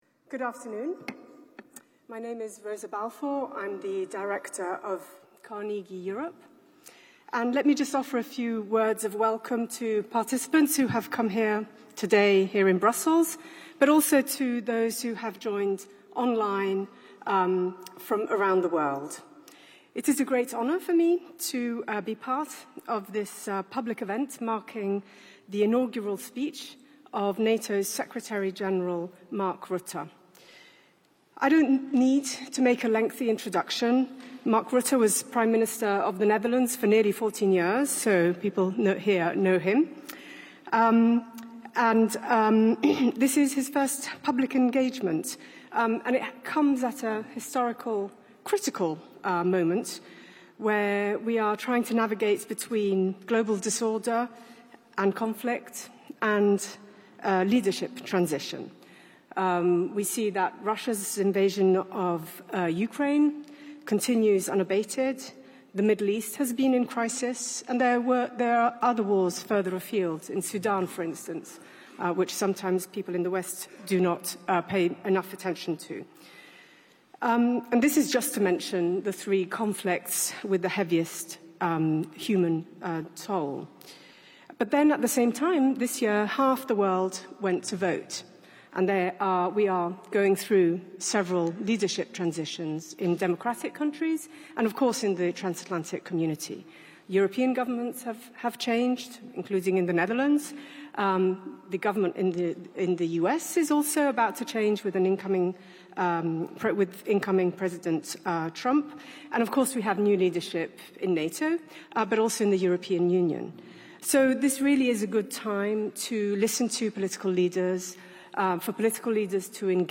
Ομιλία του Γενικού Γραμματέα του ΝΑΤΟ Mark Rutte στο Concert Noble, Βρυξέλλες 12 Δεκεμβρίου 2024